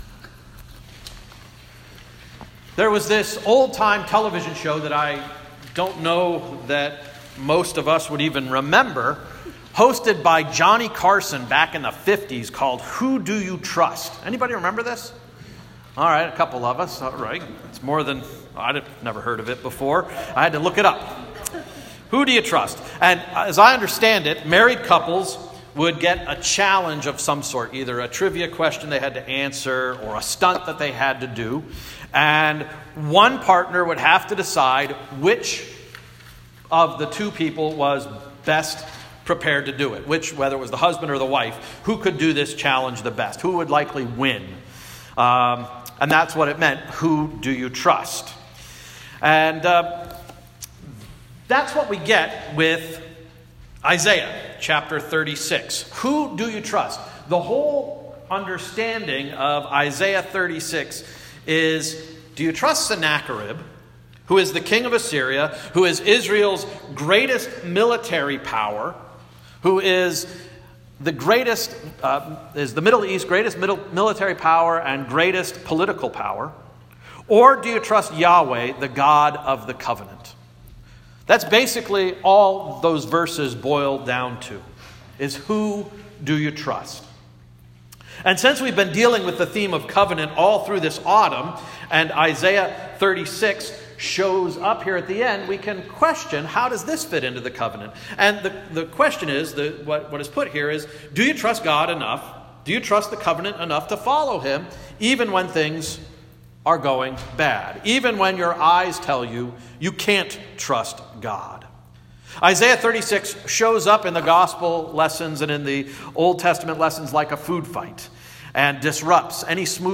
Sermon of November 18, 2018 — “Who do you trust?”